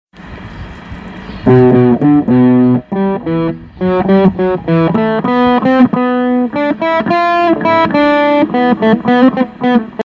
homemade guitar